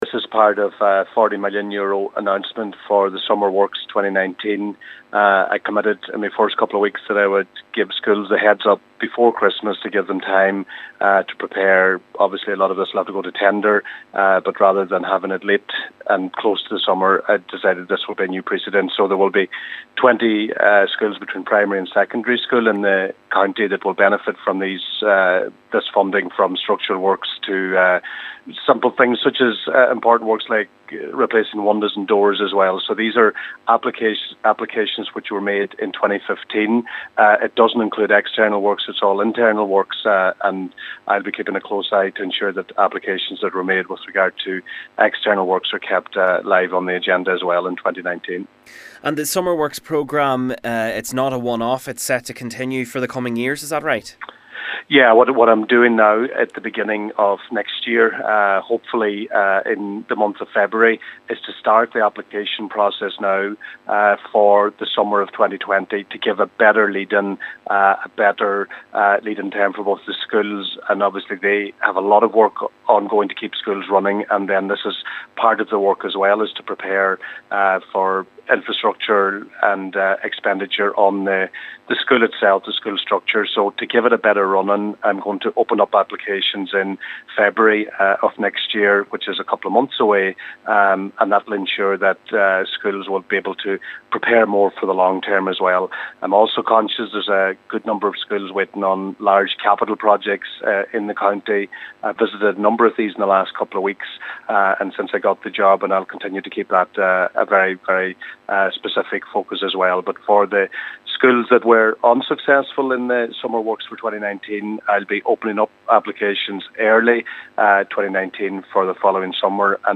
Education Minister Joe McHugh made the announcement and says while this funding is for internal works only, investment for external works is still on the agenda………………..